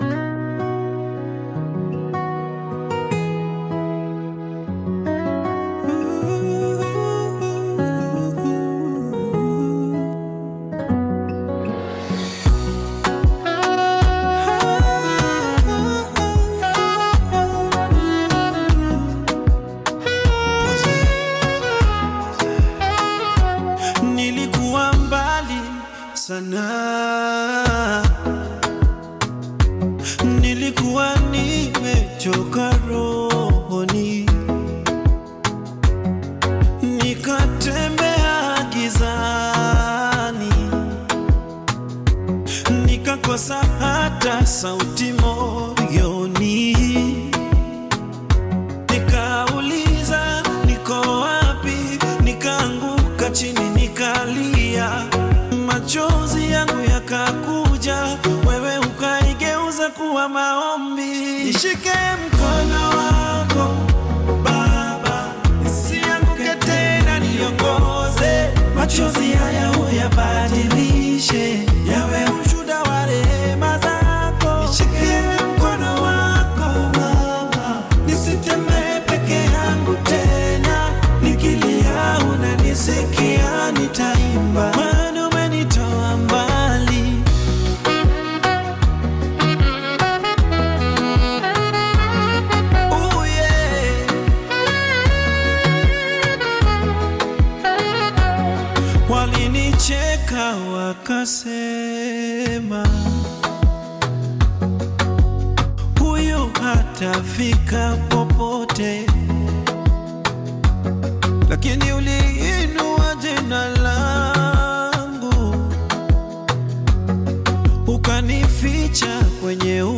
heartfelt Afro-Pop/Gospel-influenced single
contemporary African sound
soulful vocals